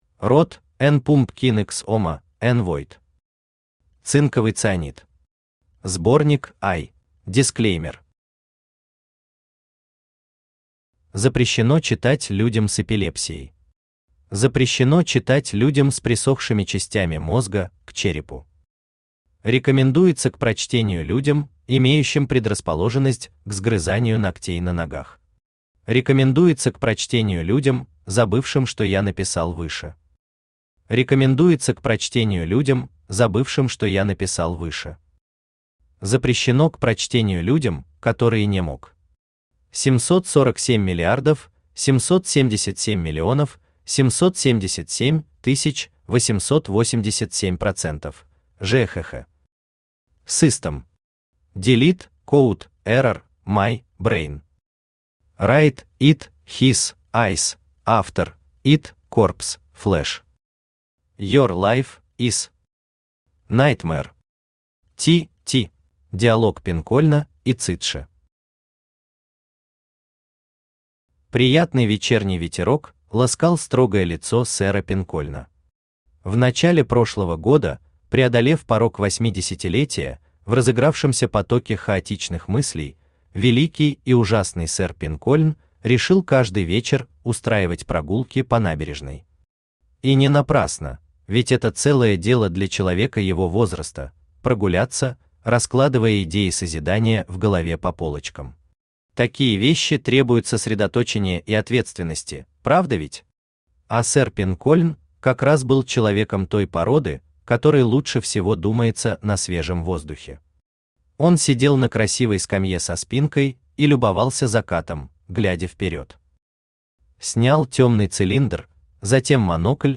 Сборник I Автор Røttėn Pümpkin x Ømėn Vøid Читает аудиокнигу Авточтец ЛитРес.